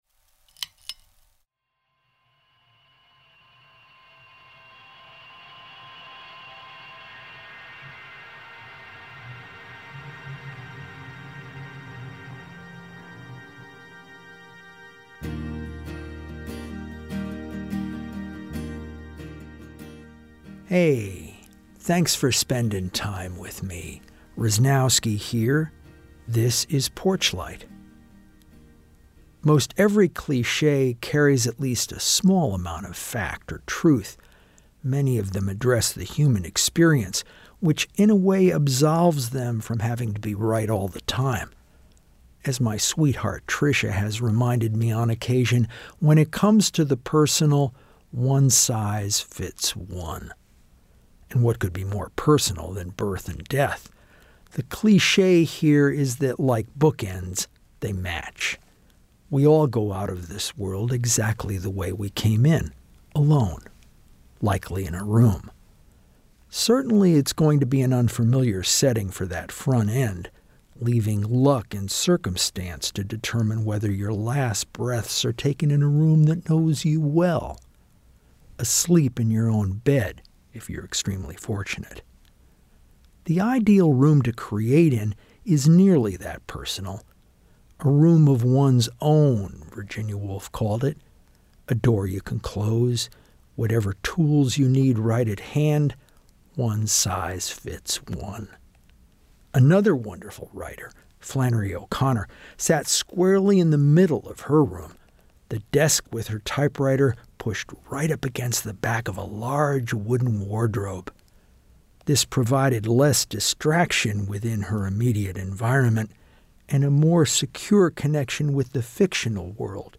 Personal spaces examined in story and song.